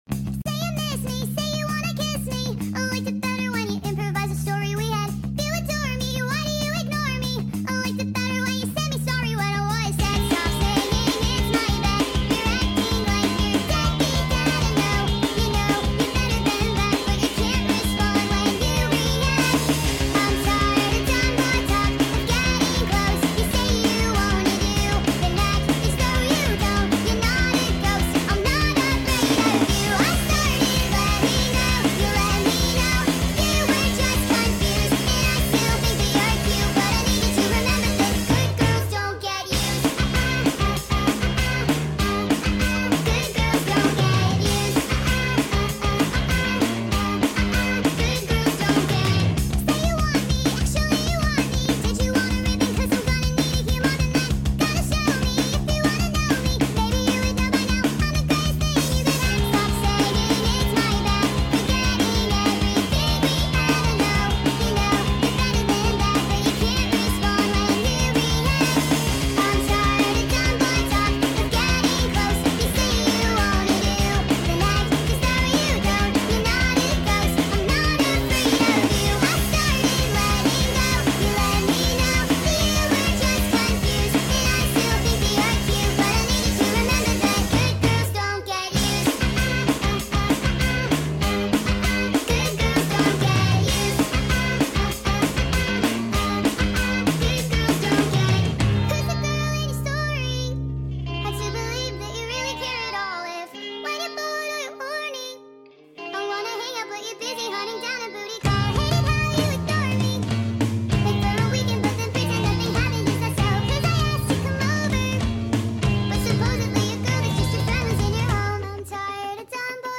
NIGHTCORE